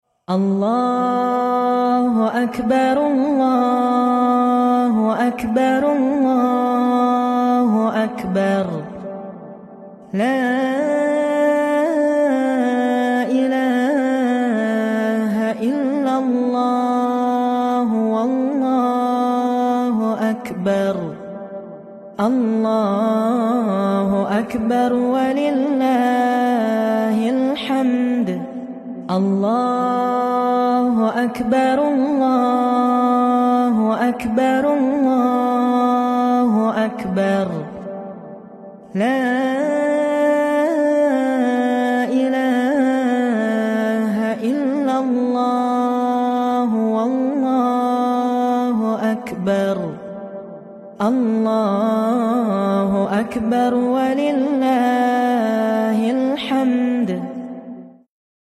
Takbir